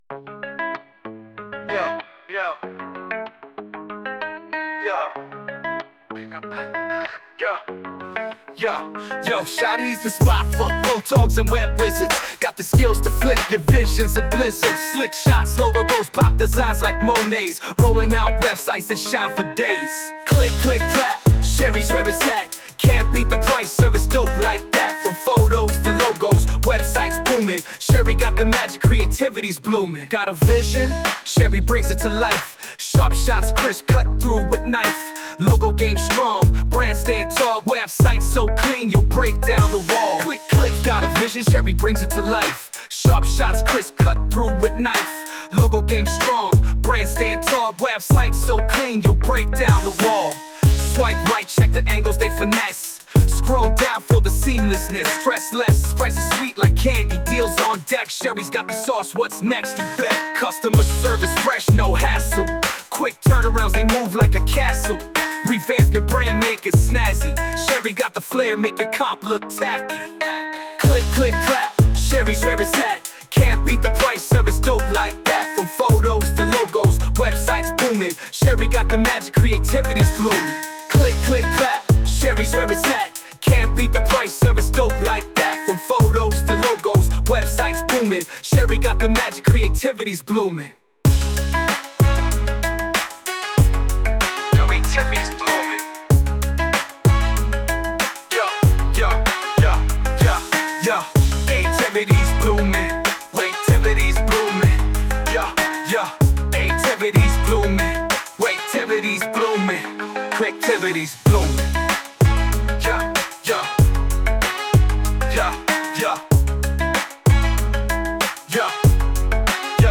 Music Genre: Hip-Hop (Male Singer)